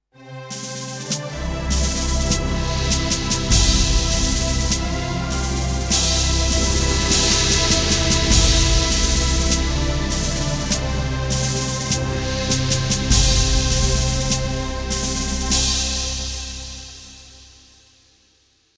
Cortina musical
Sonidos: Música